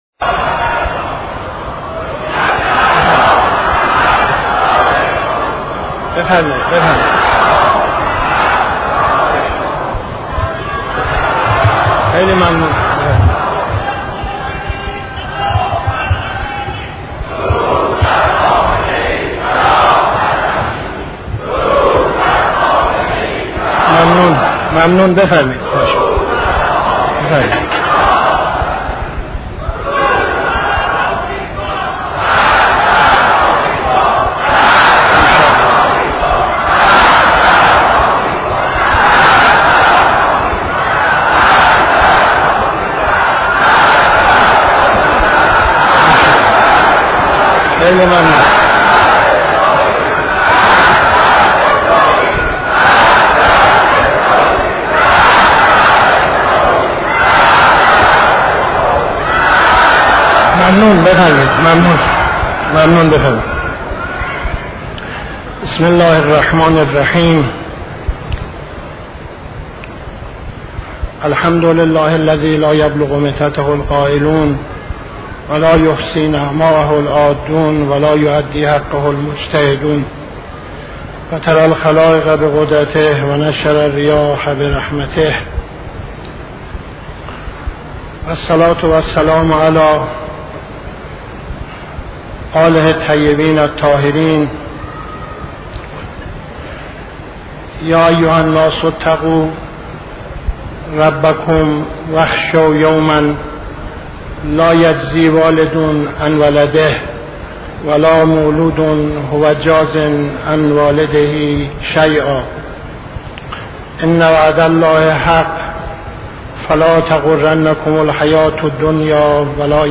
خطبه اول نماز جمعه 13-06-71